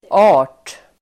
Uttal: [a:r_t]